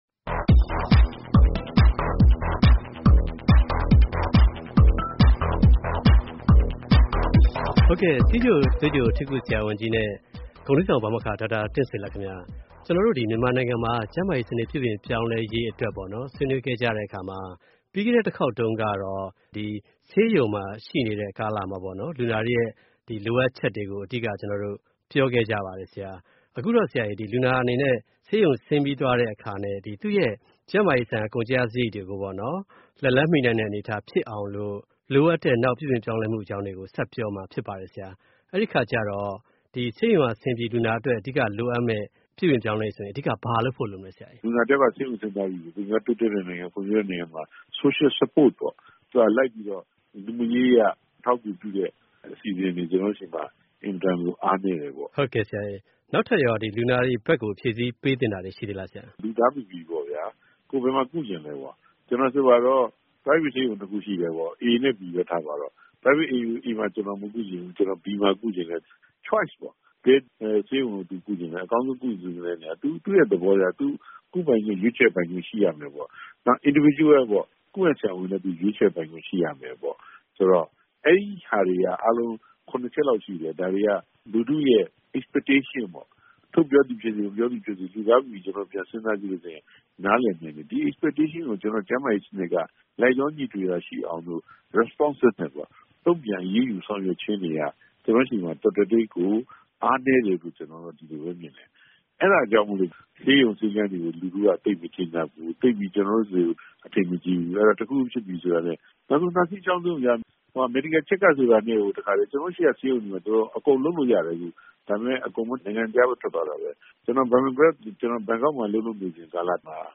ဆက်သွယ်မေးမြန်း ဆွေးနွေးတင်ပြထားပါတယ်။